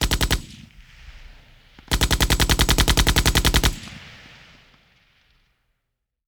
Run Machine Gun.wav